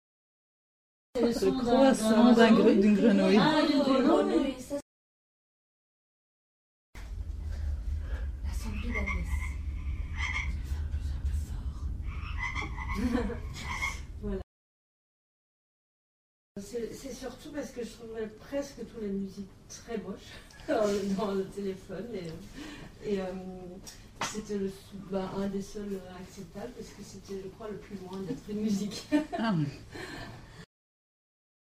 Sonnerie de GSM